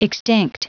Prononciation du mot extinct en anglais (fichier audio)
Prononciation du mot : extinct